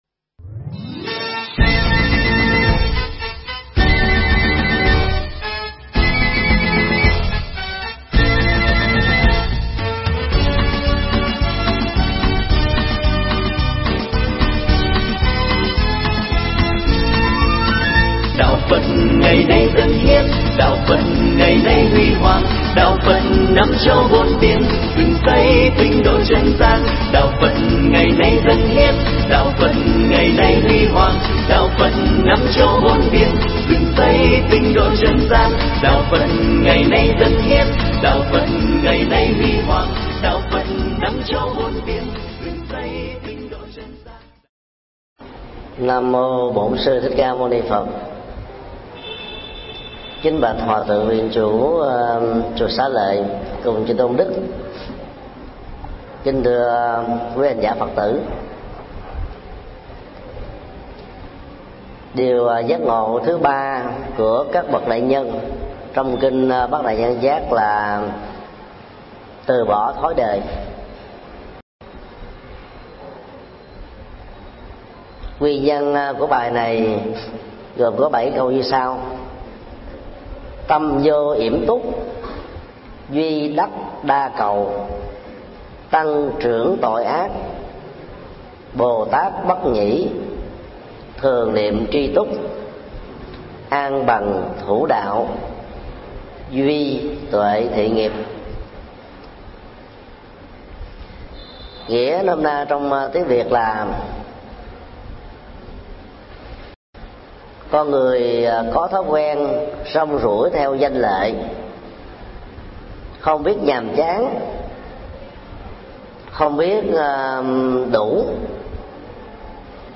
Mp3 Pháp Thoại Bát đại nhân giác 03: Từ bỏ thói đời
giảng tại chùa Xá Lợi